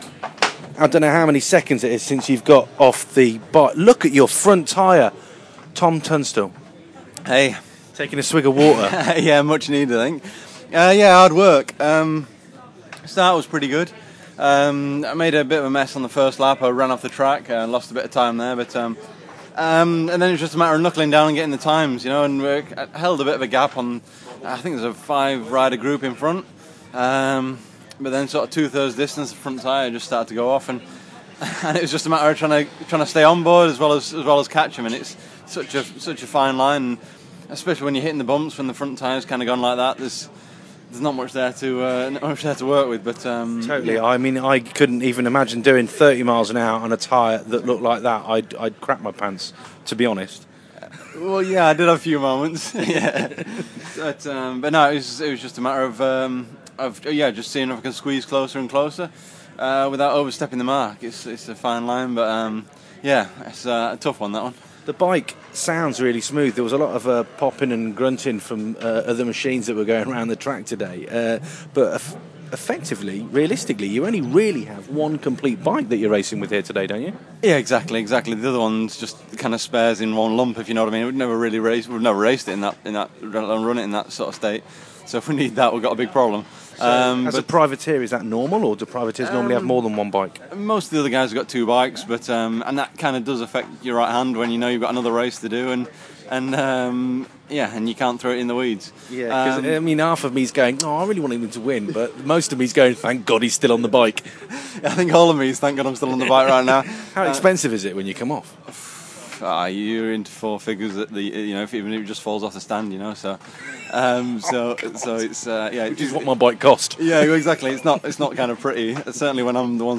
at British Superbikes